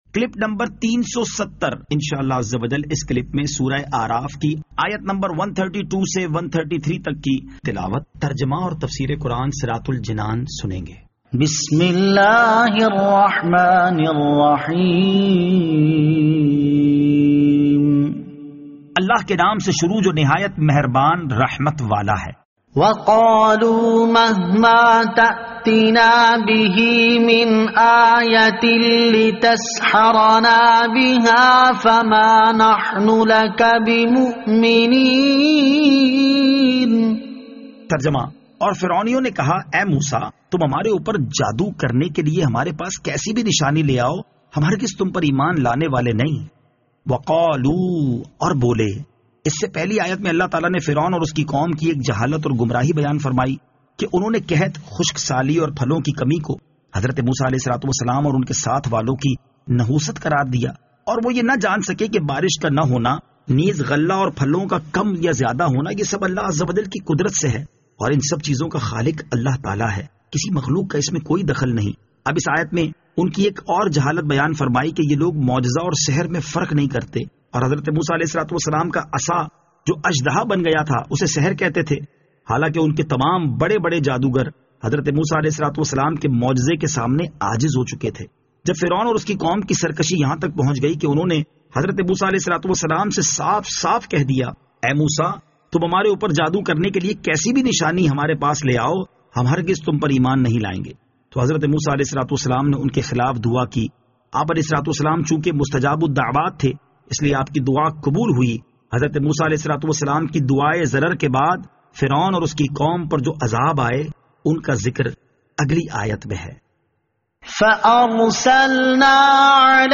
Surah Al-A'raf Ayat 132 To 133 Tilawat , Tarjama , Tafseer